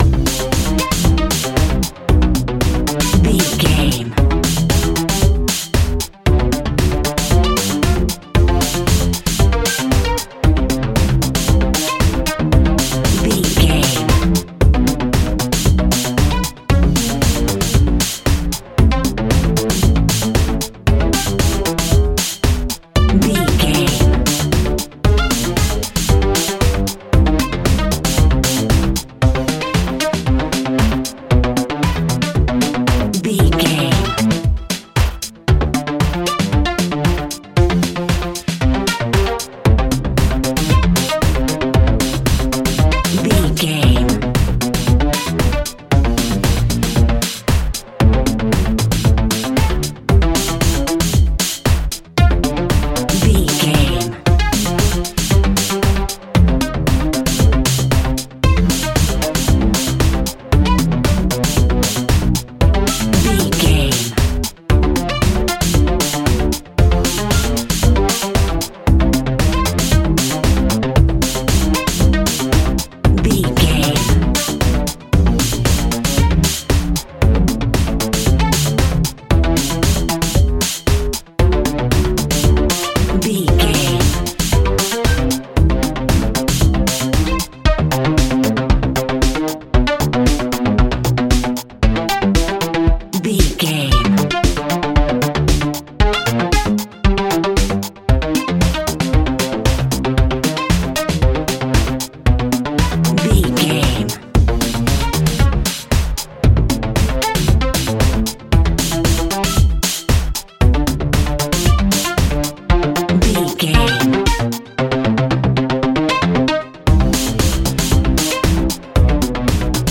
Fast paced
Aeolian/Minor
Fast
driving
intense
energetic
drum machine
synthesiser
electro house
synth pop
house music